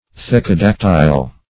Search Result for " thecodactyl" : The Collaborative International Dictionary of English v.0.48: Thecodactyl \The`co*dac"tyl\, n. [? case + ? finger.] (Zool.) Any one of a group of lizards of the Gecko tribe, having the toes broad, and furnished with a groove in which the claws can be concealed.